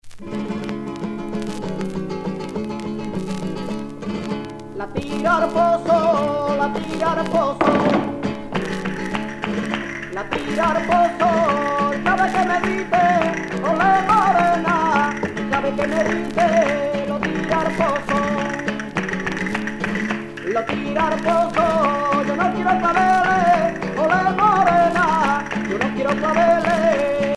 Sevillana
danse : sevillana
Pièce musicale éditée